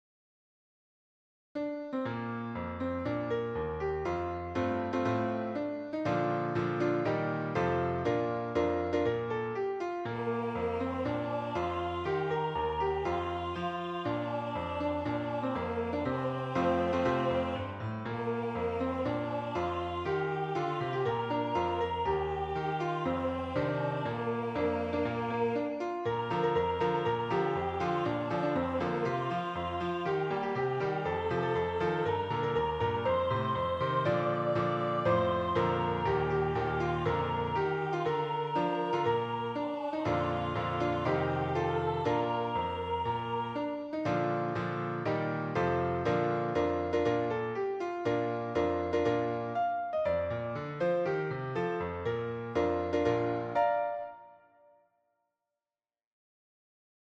メロディ